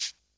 Crashes & Cymbals
pcp_shaker01.wav